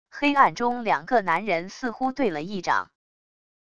黑暗中两个男人似乎对了一掌wav音频